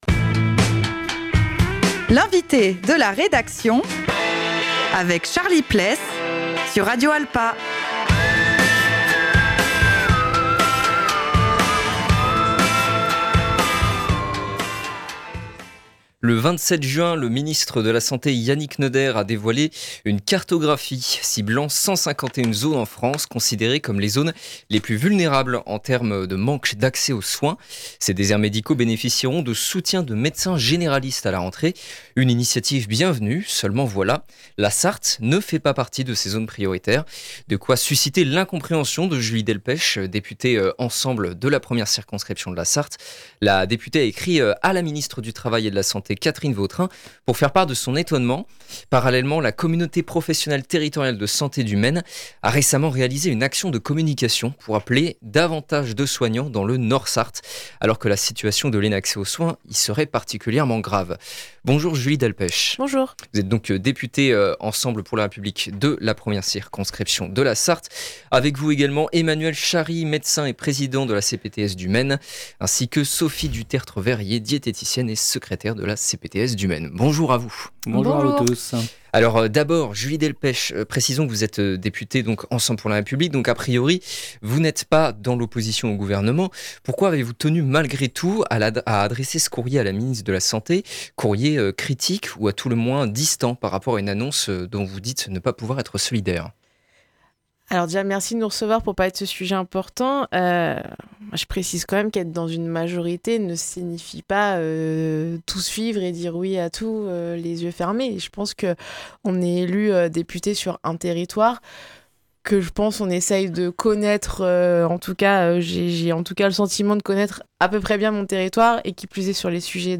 Pour parler de ce sujet, nous sommes donc avec Julie Delpech, députée EPR de la 1ere circonscription de la Sarthe,